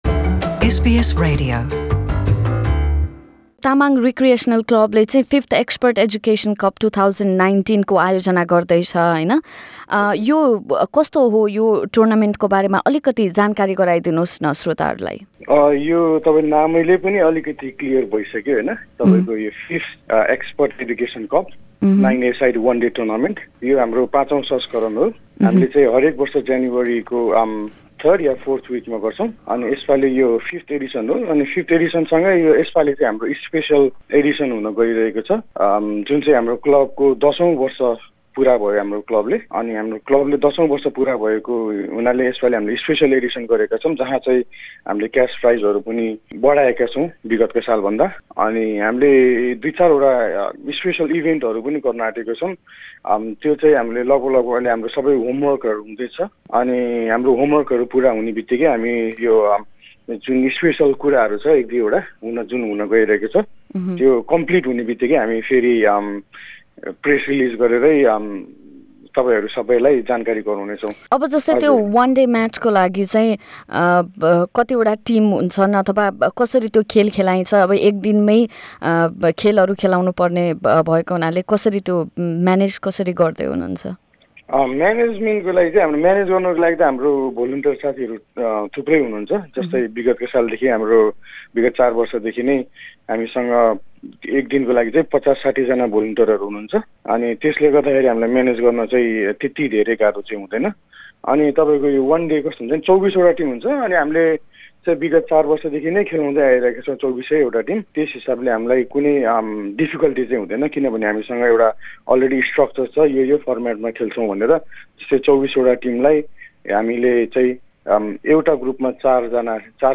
पुरा कुराकानी